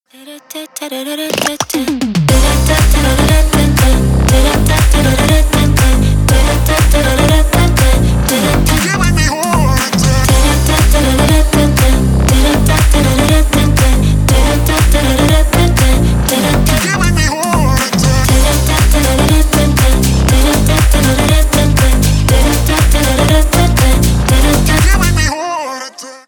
клубные
ремиксы